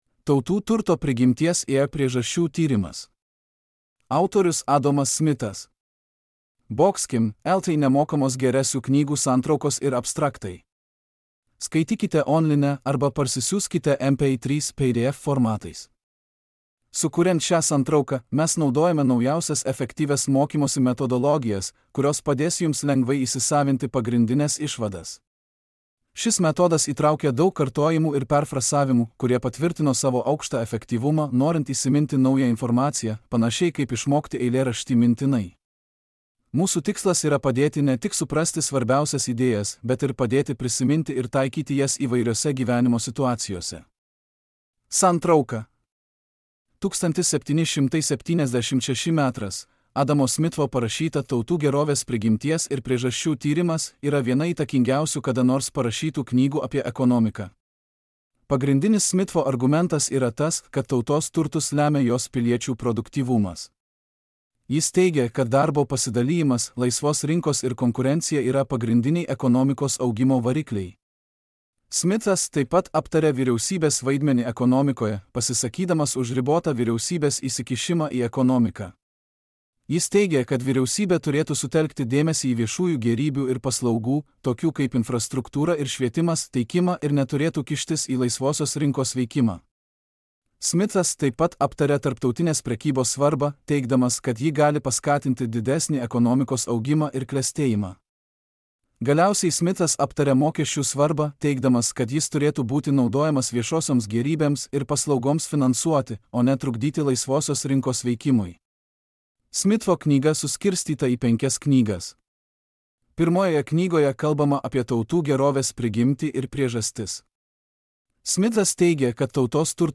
Adomas Smitas: Tautų turto prigimties ir priežasčių tyrimas | Knygos santrauka | Skaityti online arba atsisiųsti MP3 / PDF formatu be registracijos.